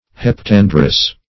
heptandrous - definition of heptandrous - synonyms, pronunciation, spelling from Free Dictionary
Search Result for " heptandrous" : The Collaborative International Dictionary of English v.0.48: Heptandrian \Hep*tan"dri*an\, Heptandrous \Hep*tan"drous\, a. [Cf. F. heptandre.]